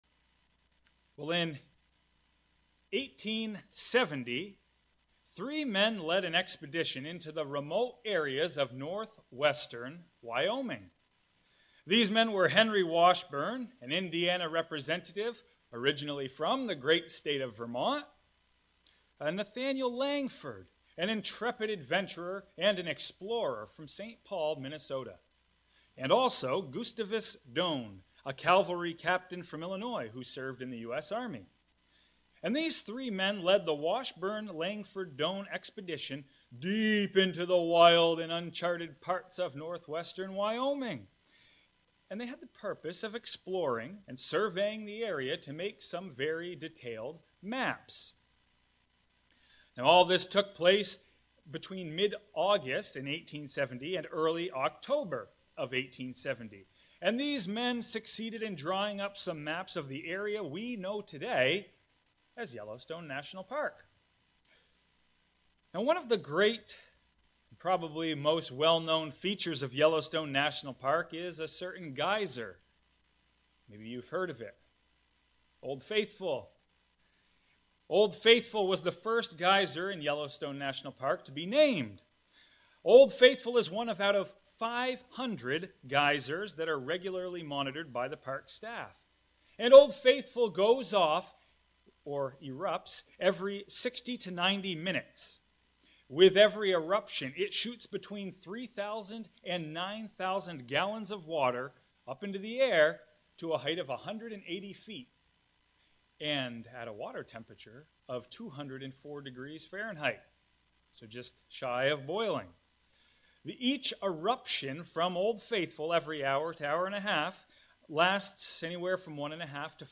Sermons
Given in Fargo, ND